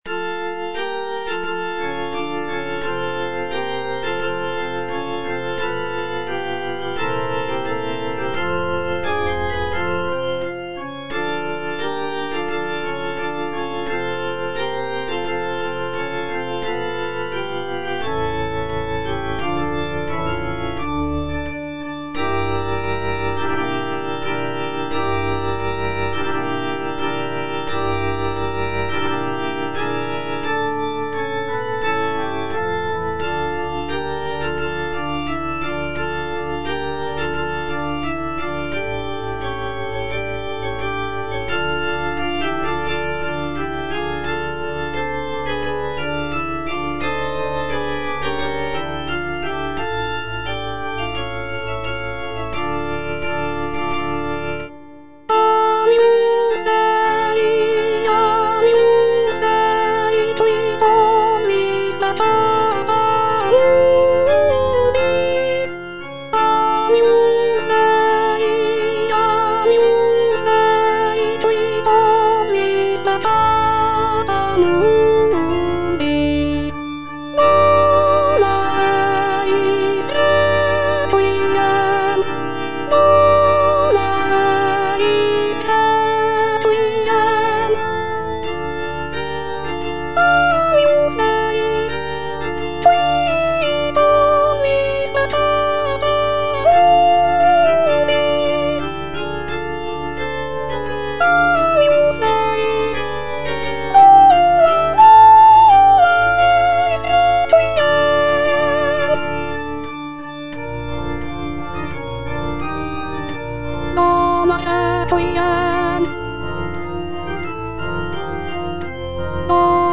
F. VON SUPPÈ - MISSA PRO DEFUNCTIS/REQUIEM Agnus Dei - Soprano (Voice with metronome) Ads stop: auto-stop